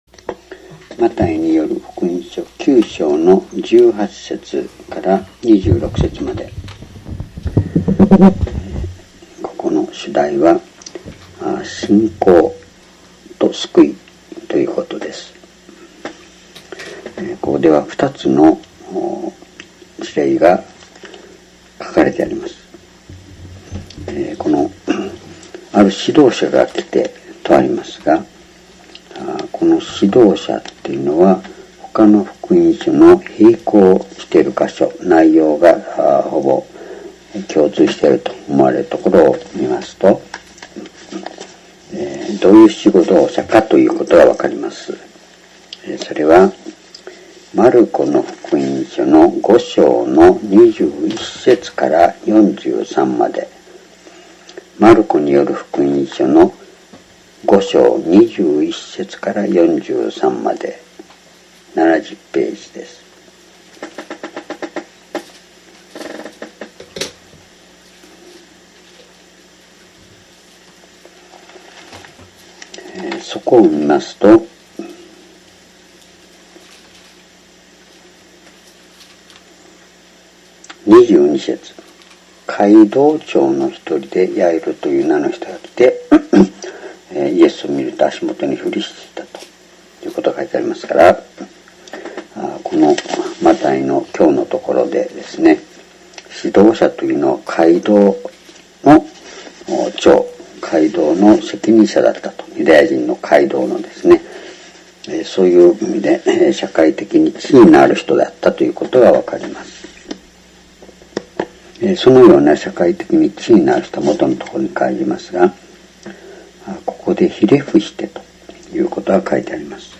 主日礼拝日時 2017年4月9日 聖書講話箇所 マタイ福音書9章18-26 「イエスの信仰による救い」 ※視聴できない場合は をクリックしてください。